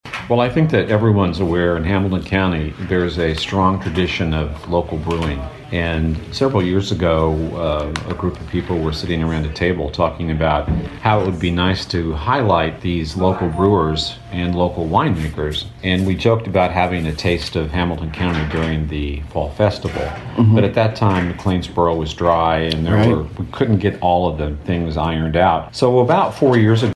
Fred Vallowe, McLeansboro City Clerk, was a guest on WROY’s Open Line program recently and explained how the event came about…